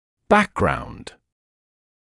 [‘bækgraund][‘бэкграунд]исходные данные; данные о приосхождении или образовании